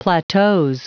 Prononciation du mot plateaux en anglais (fichier audio)
Prononciation du mot : plateaux